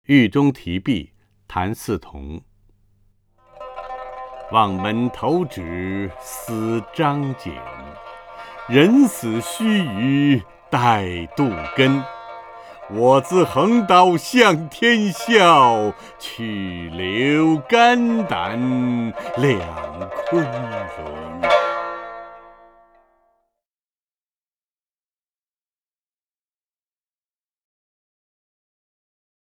陈铎朗诵：《狱中题壁》(（清）谭嗣同) （清）谭嗣同 名家朗诵欣赏陈铎 语文PLUS
（清）谭嗣同 文选 （清）谭嗣同： 陈铎朗诵：《狱中题壁》(（清）谭嗣同) / 名家朗诵欣赏 陈铎